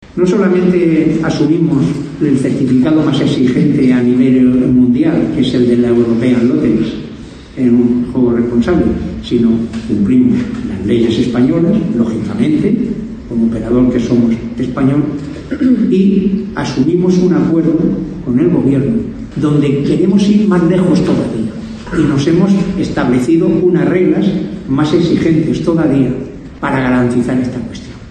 Mesa inaugural de las jornadas de Fejar